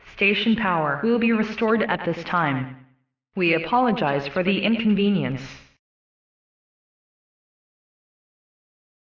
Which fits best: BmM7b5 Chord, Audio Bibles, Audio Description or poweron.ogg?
poweron.ogg